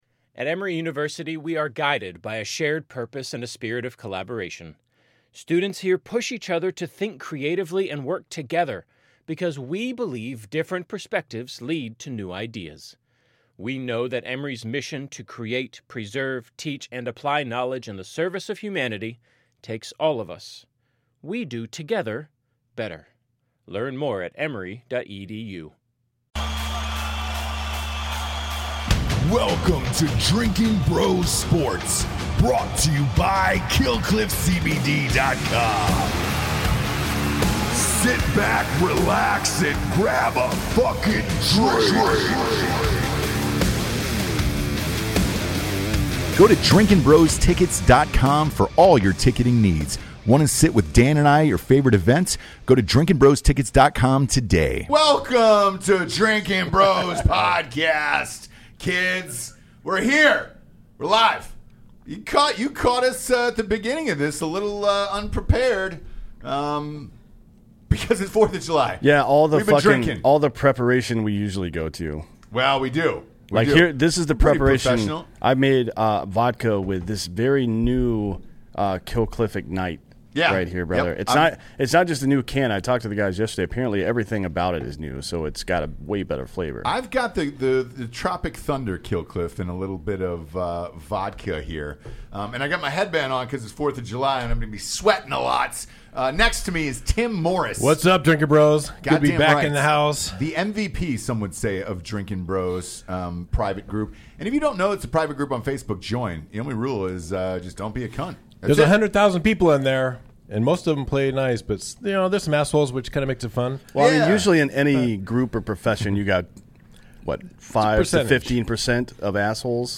Episode 631 - Live From Nathan's Famous Hot Dog Eating Contest